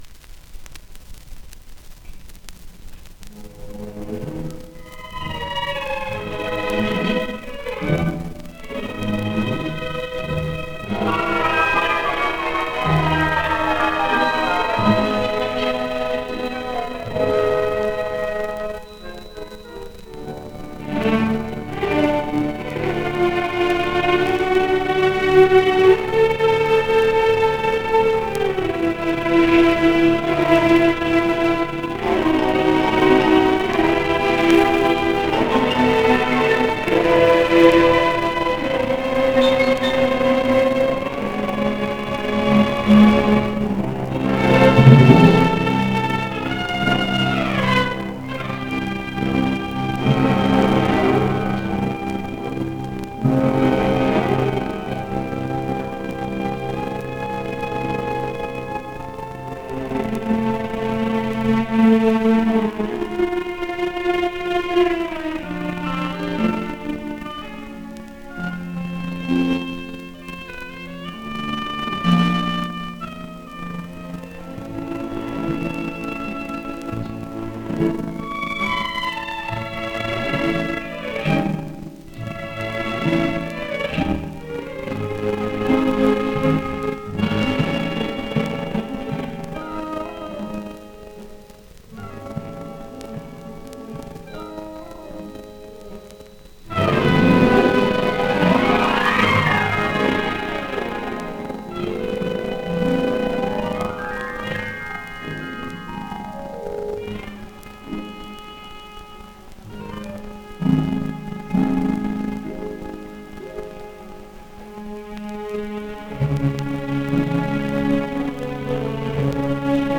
2 discos : 78 rpm ; 30 cm
• Música orquestal